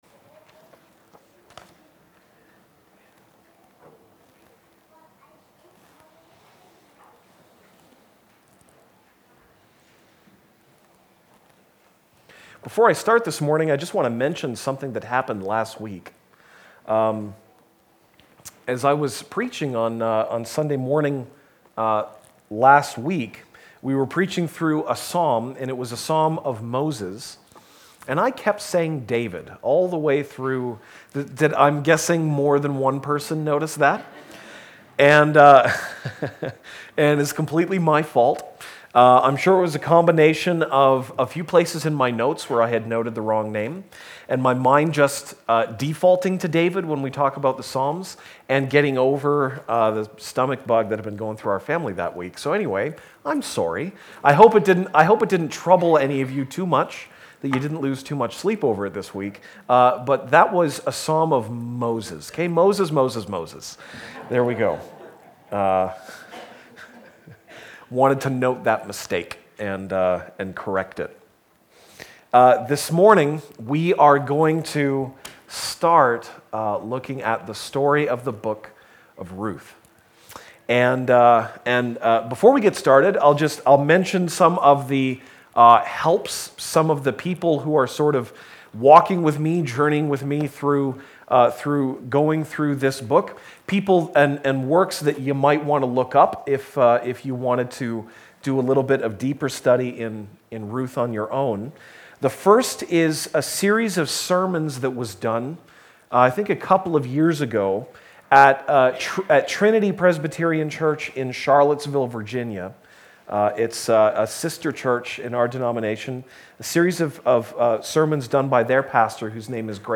January 7, 2018 (Sunday Morning)